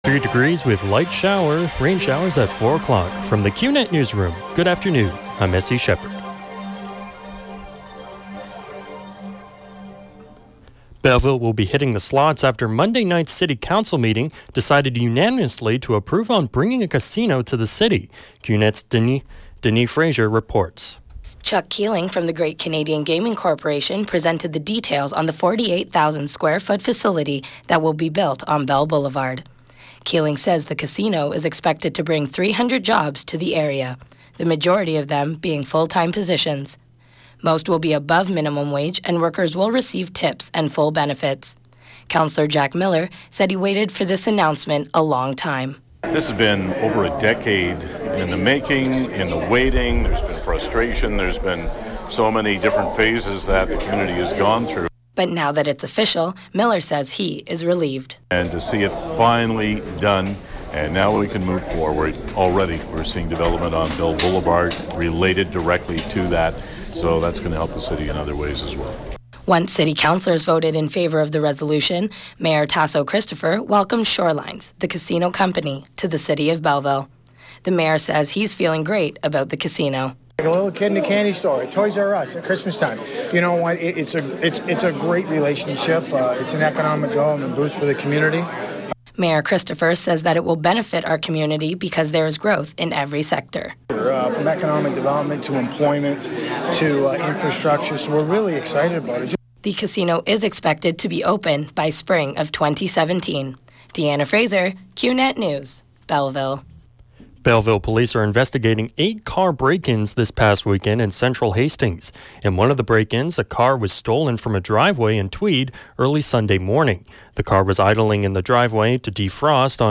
91X newscast – Tuesday, Jan. 26, 2016 – 4 p.m.